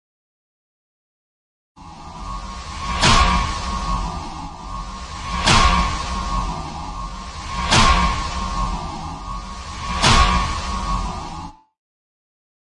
风管
描述：计算机产生的风状声音与铿锵的金属。
Tag: 背景声音 焦虑 怪异 悬疑 恐怖 可怕 闹鬼 戏剧 戏剧 让人毛骨悚然 恶梦 恐怖